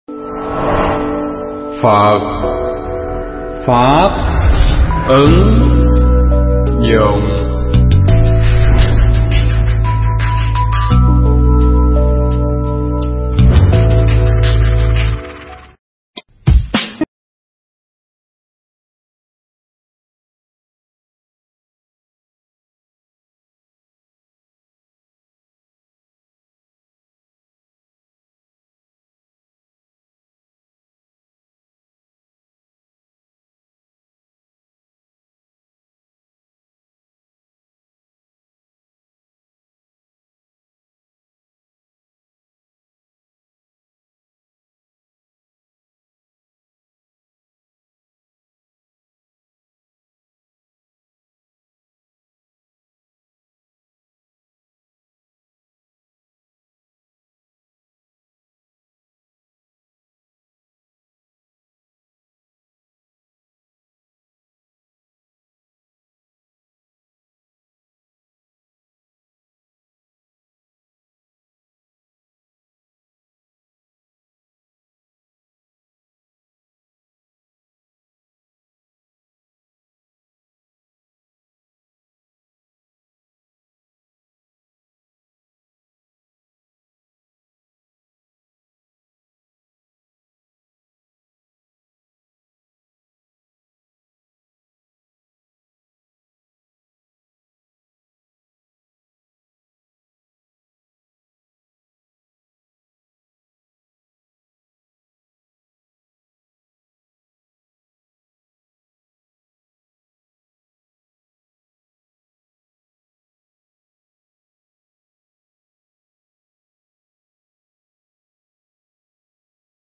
Mp3 Pháp thoại Thông Điệp Của Người Già english sub
giảng tại chùa Ấn QUang (quận 19, Tp.HCM)